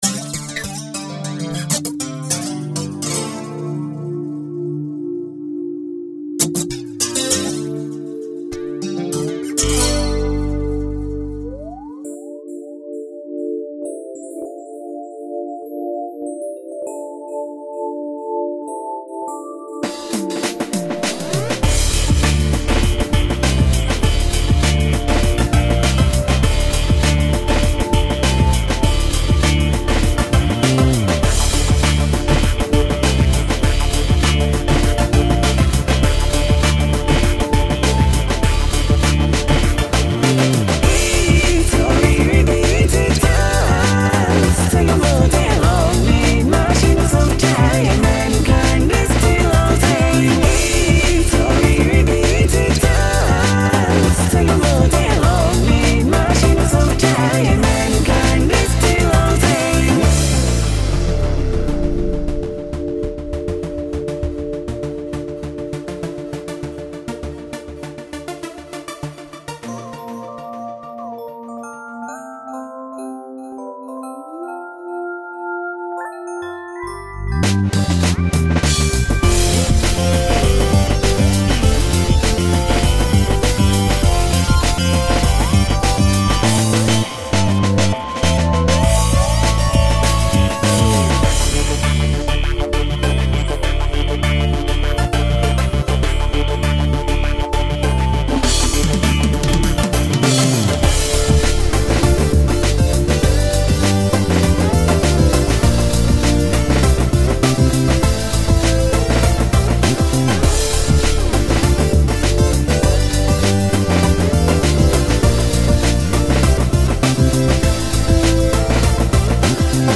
remix
catchy AF! https